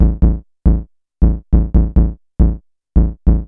BASS GROOV-R.wav